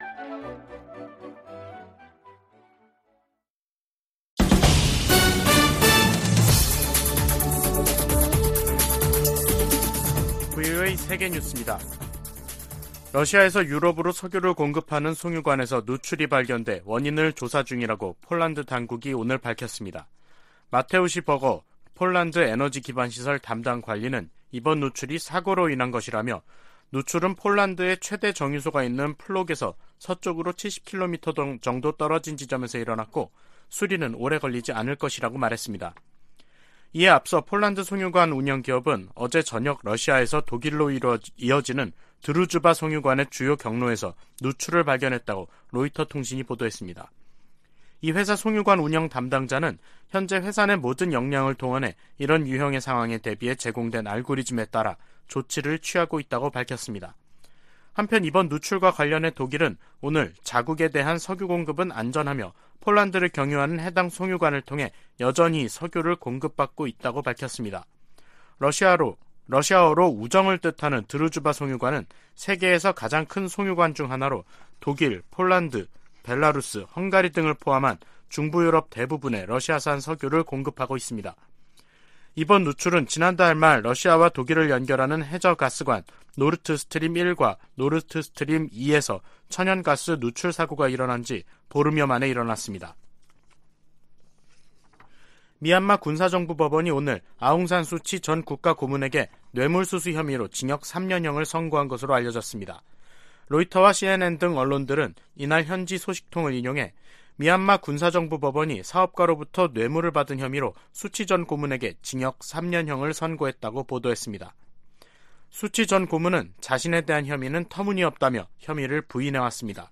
VOA 한국어 간판 뉴스 프로그램 '뉴스 투데이', 2022년 10월 12일 3부 방송입니다. 한국 일각에서 전술핵 재배치 주장이 나오는 데 대해 백악관 고위 당국자는 비핵화 목표를 강조했습니다. 북한이 최근 '전술핵 운용부대 훈련'을 전개했다며 공개한 사진 일부가 재활용된 것으로 파악됐습니다. 미국 정부가 핵을 포함한 모든 범위의 확장 억지 공약을 재확인했습니다.